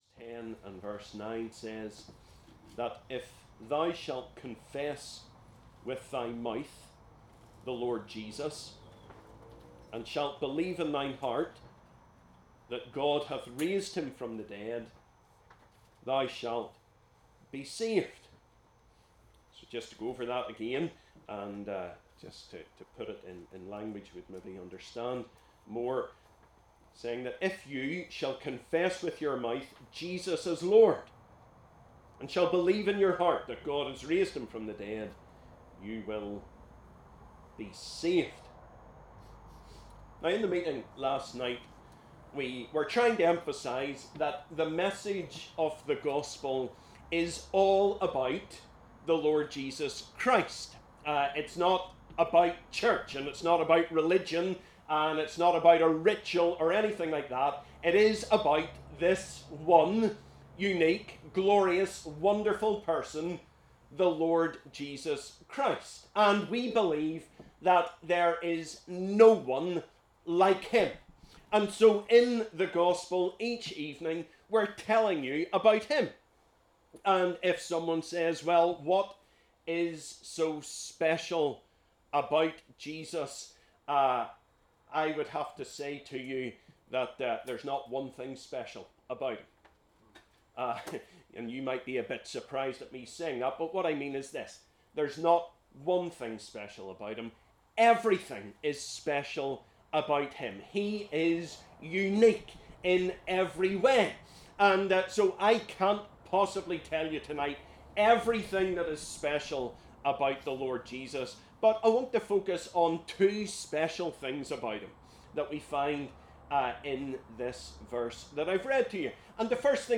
Gospel Tent ’23: “Romans 10:9” and “John 1:29” (49 mins)